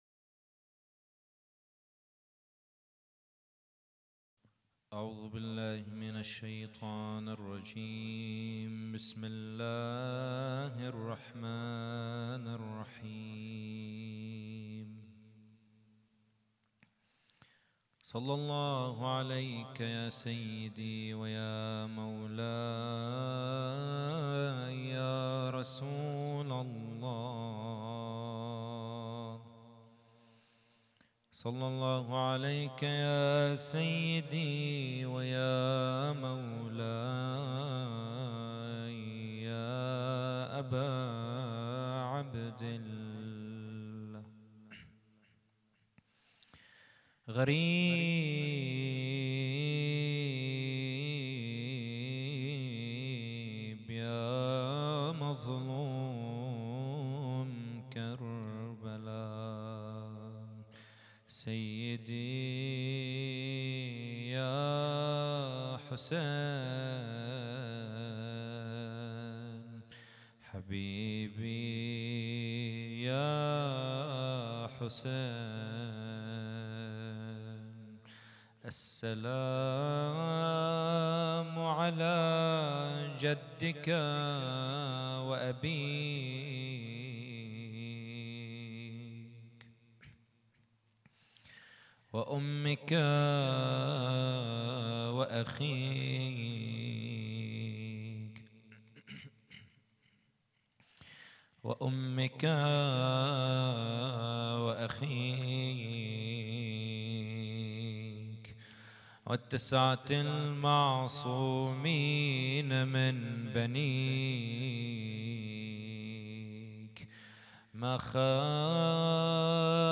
محاضرة
احياء الليلة الخامسة من محرم 1442 ه.ق - هیأت رایة العباس لبنان